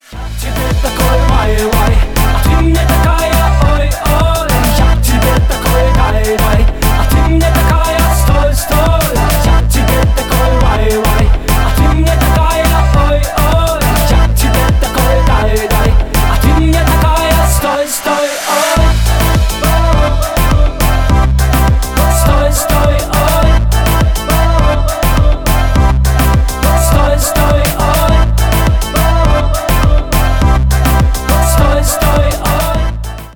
Поп Музыка
кавказские # весёлые